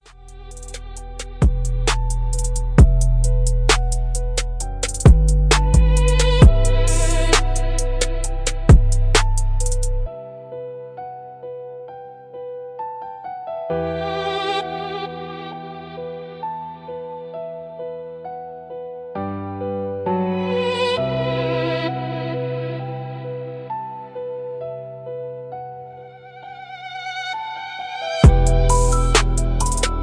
Extra-crispy trap snare, poetic melancholic.